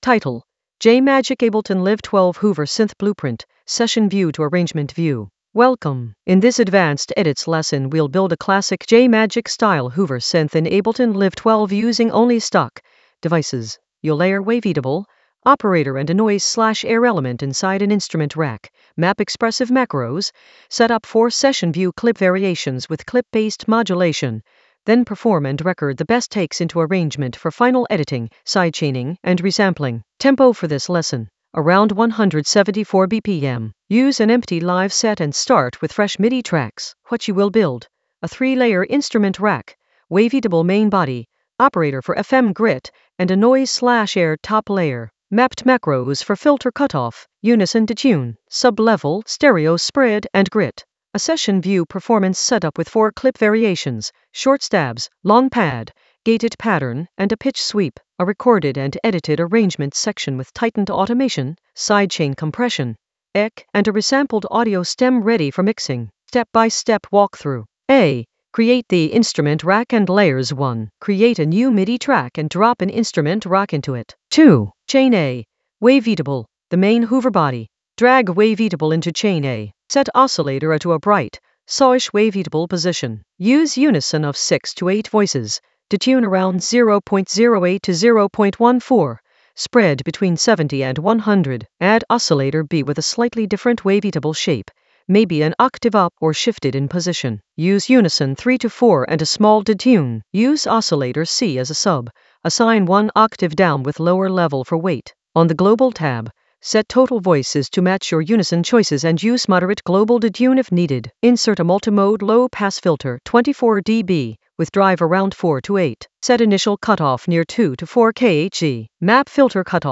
An AI-generated advanced Ableton lesson focused on J Majik Ableton Live 12 hoover synth blueprint using Session View to Arrangement View in the Edits area of drum and bass production.
Narrated lesson audio
The voice track includes the tutorial plus extra teacher commentary.